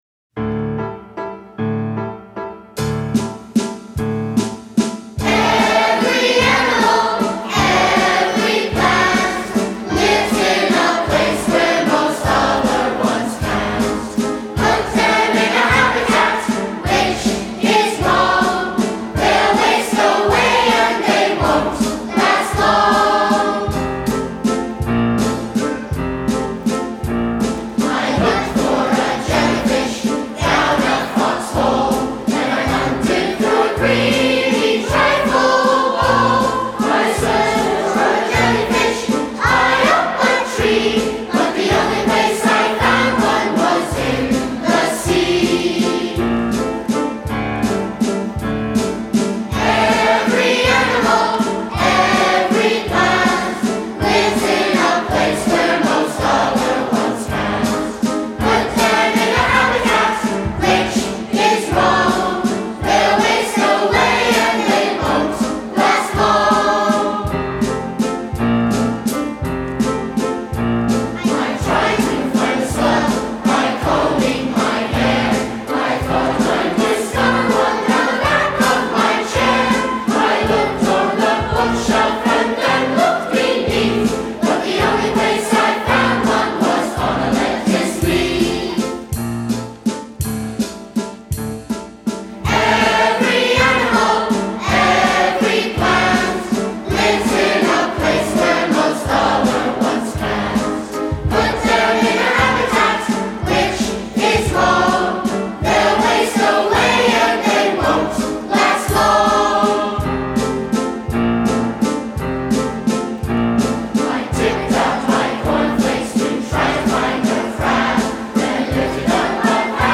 Below you can hear the 2007 Festival Chorus performing Lifetime: Songs of Life and Evolution.